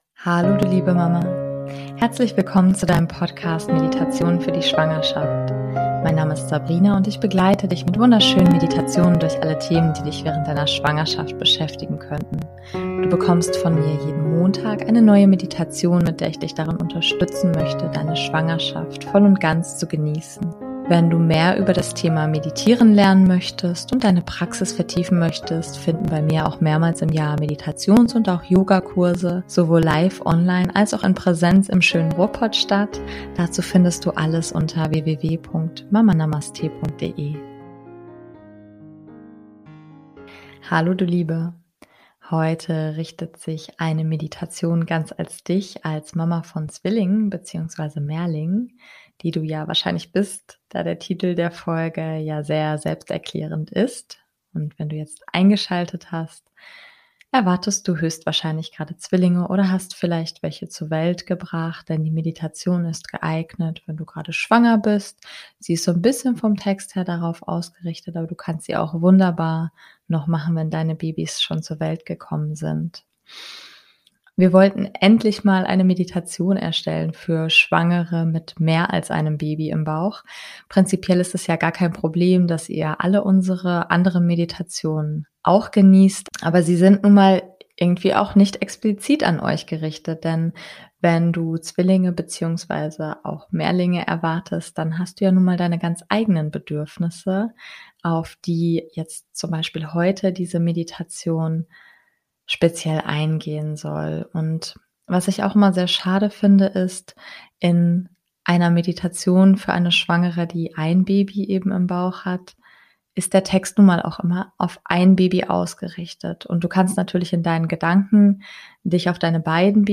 #050 - Meditation für Zwillinge - Schwanger mit zwei oder mehr Babys ~ Meditationen für die Schwangerschaft und Geburt - mama.namaste Podcast